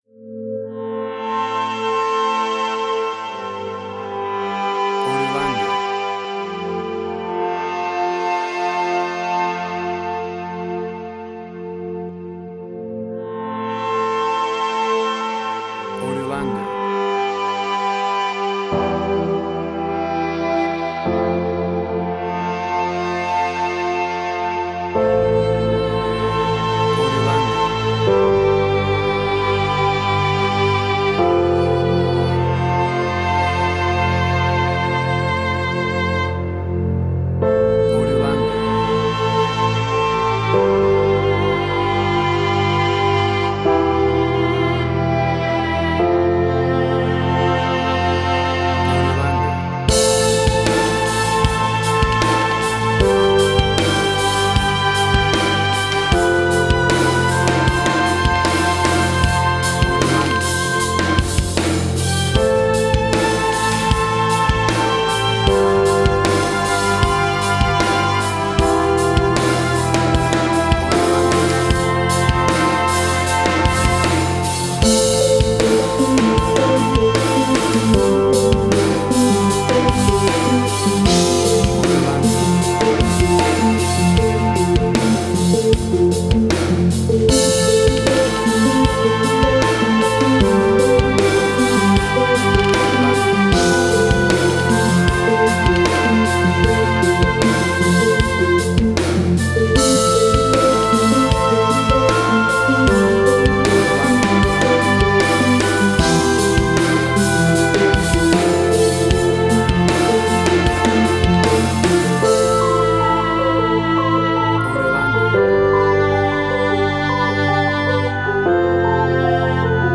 Post-Electronic.
emotional music
Tempo (BPM): 77